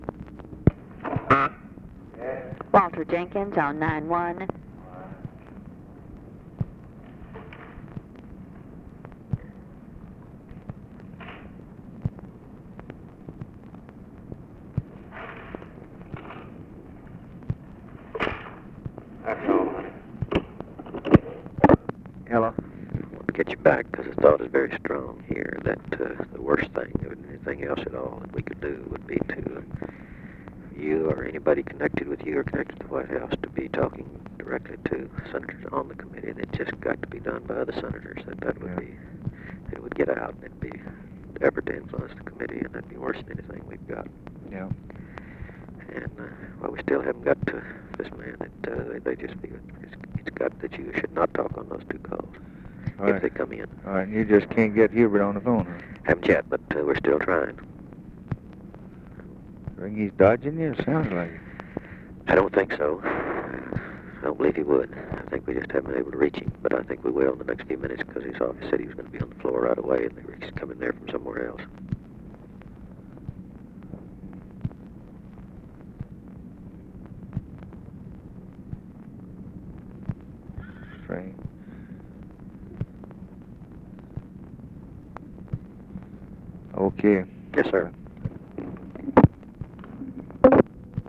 Conversation with WALTER JENKINS, January 29, 1964
Secret White House Tapes